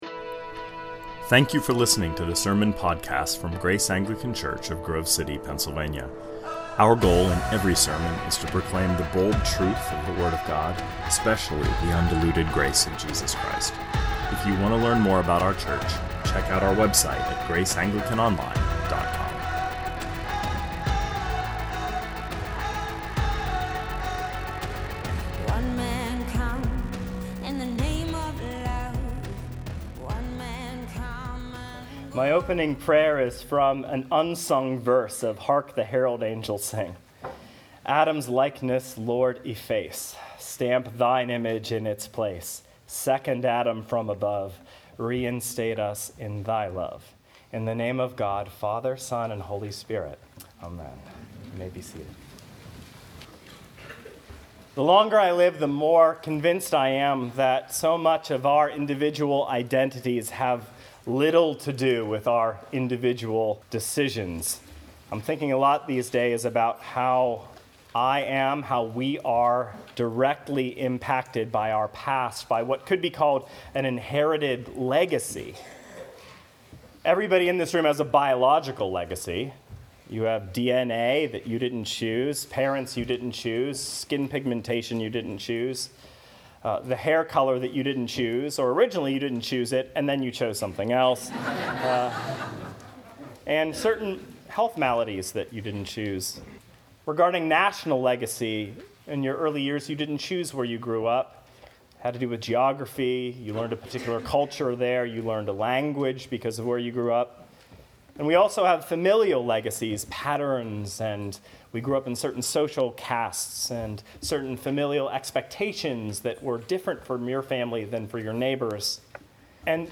2019 Sermons (Entirely) Saved - Imputation - Romans 5 Play Episode Pause Episode Mute/Unmute Episode Rewind 10 Seconds 1x Fast Forward 30 seconds 00:00 / 26:34 Subscribe Share RSS Feed Share Link Embed